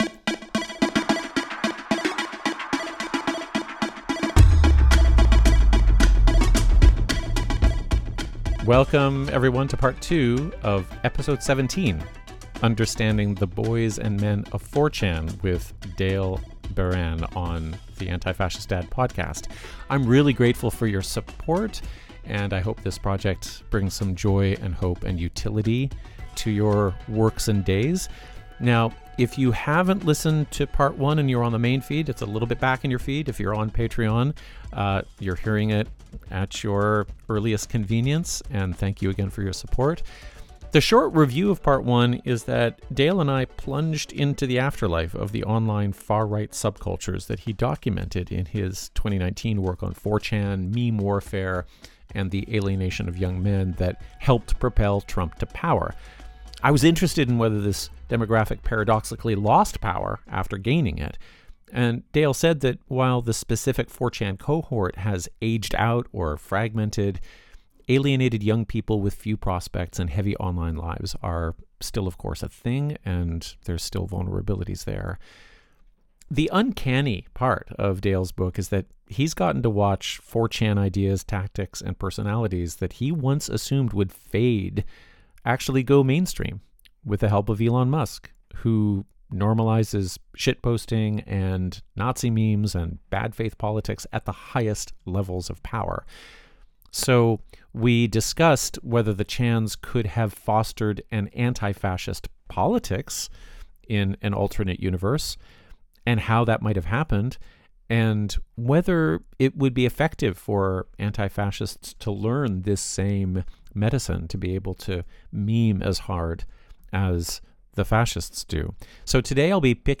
This episode ends with an informal report on how conversations about online spaces are going in this antifascist house.&nbsp